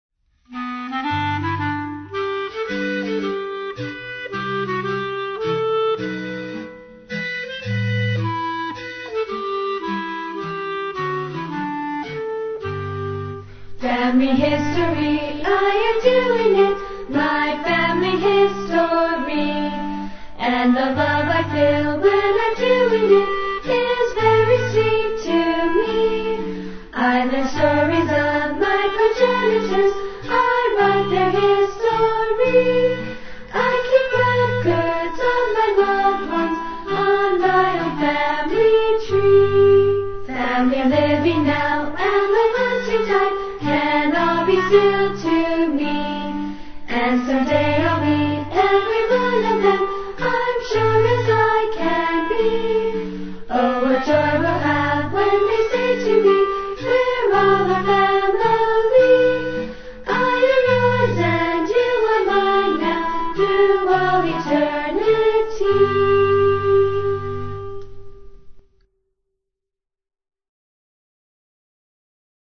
With words or Music only